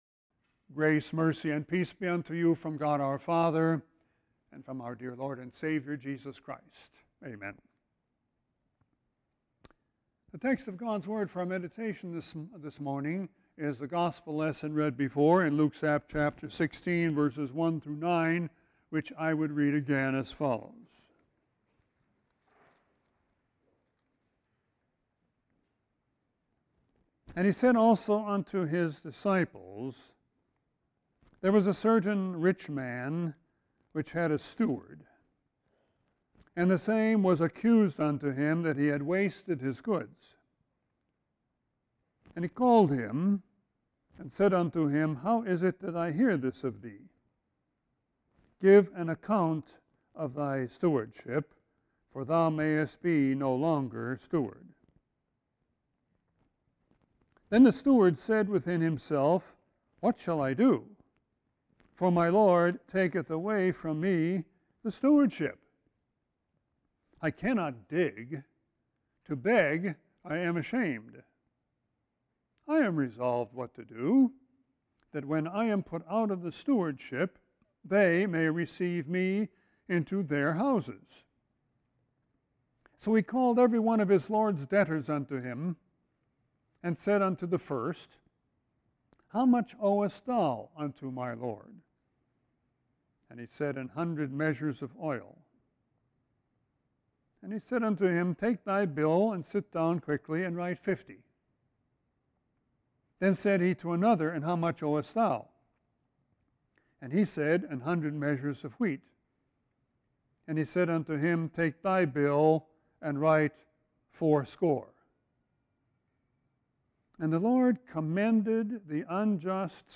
Sermon 8-18-19.mp3